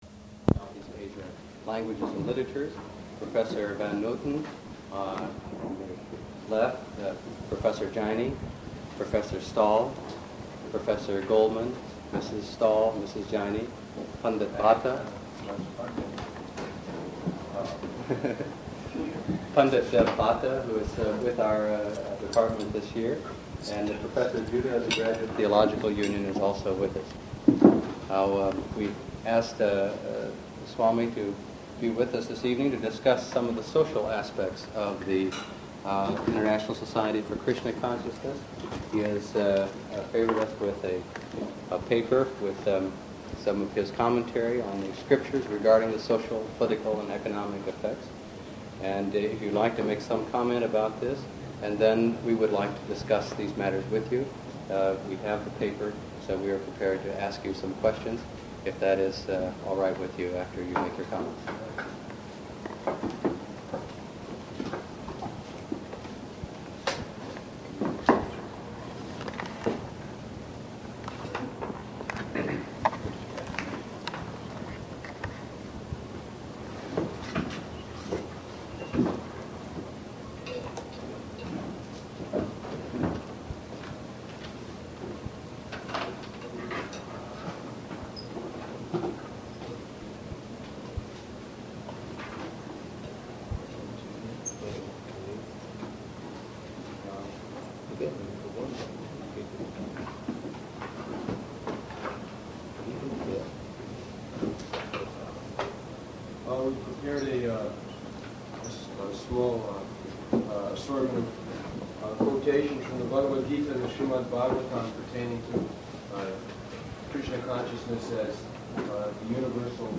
-- Type: Conversation Dated